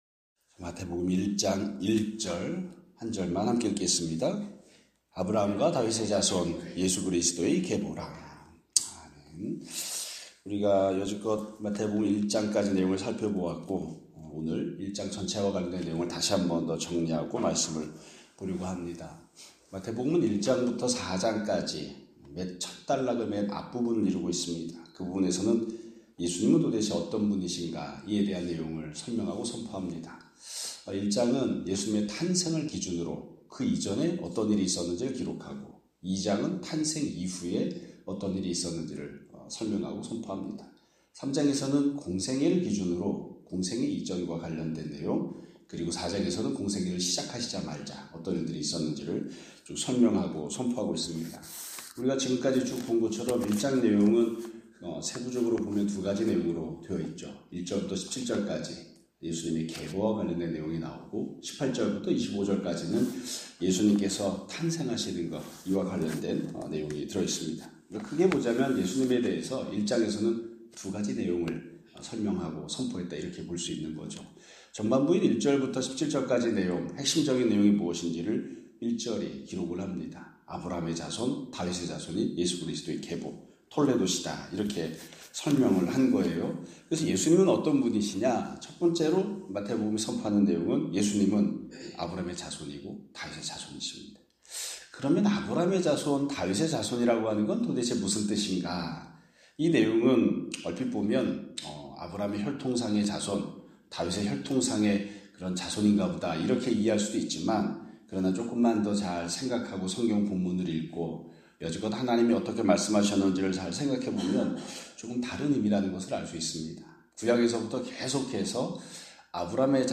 2025년 3월 28일(금요일) <아침예배> 설교입니다.